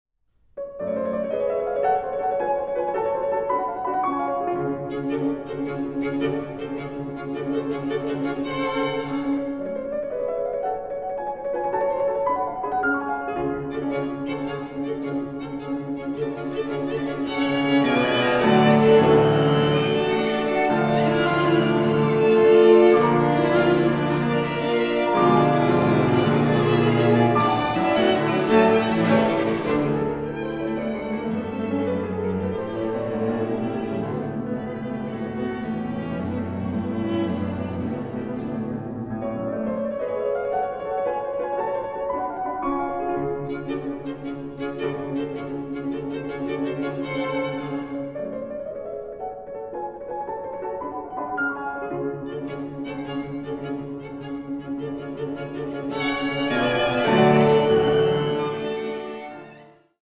Piano quartet